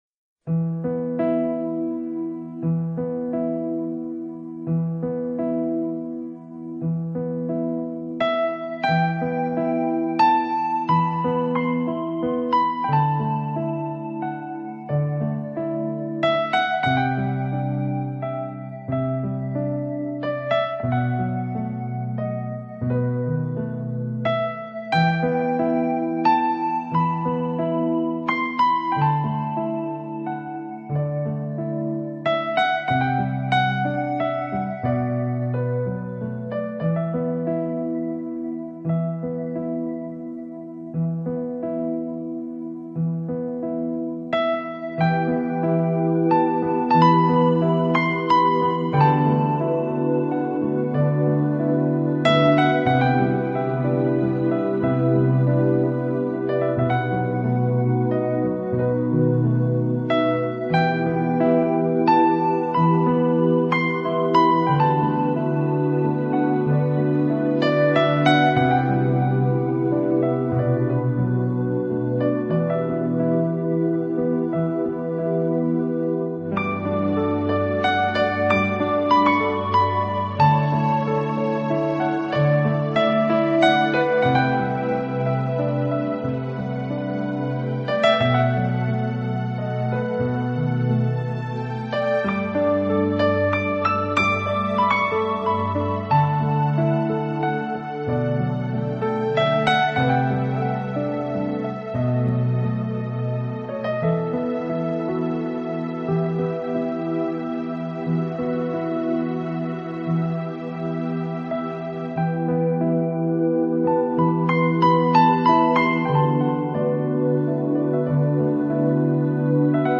【爵士钢琴】
音乐类型：Jazz